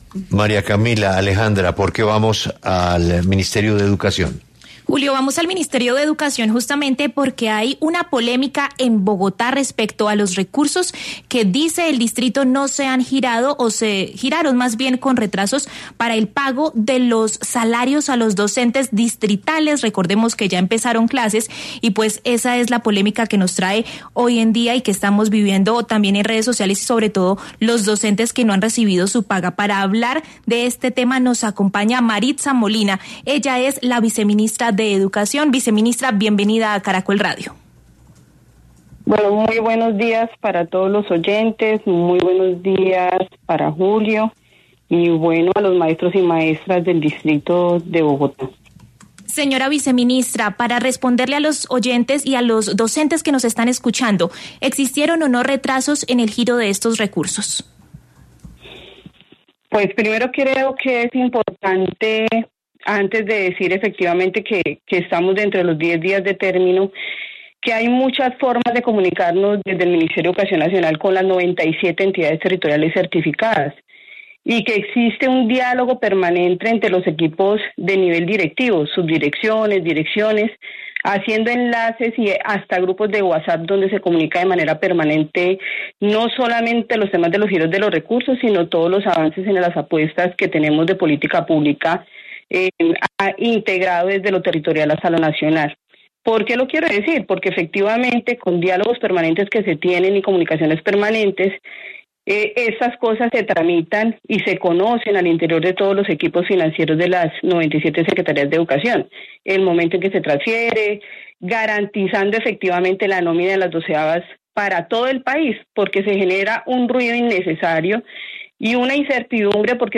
En diálogo con 6AM W de Caracol Radio, la viceministra de Educación, Maritza Molina, afirmó que los recursos del Sistema General de Participaciones (SGP) están garantizados y que no existe riesgo para el pago de la nómina.